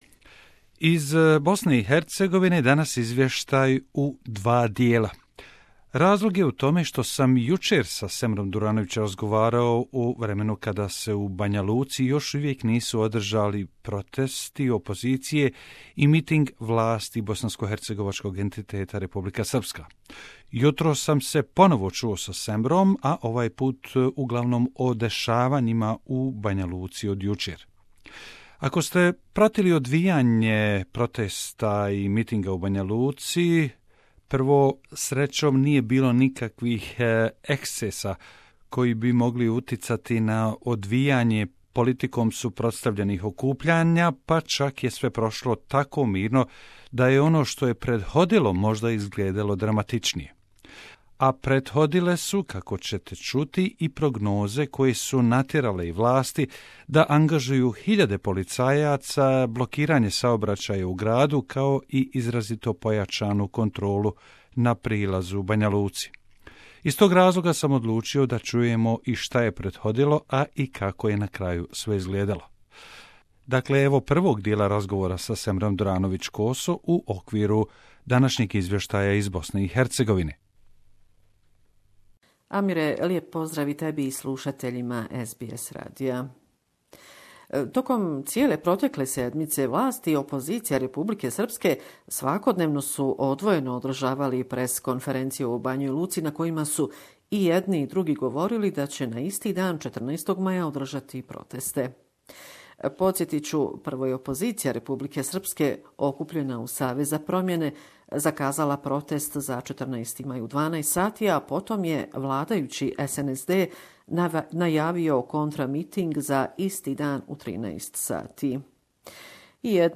Two rallies, organised in the same time and same city, showed Bosnian Serbs division Report from Bosnia and Herzegovina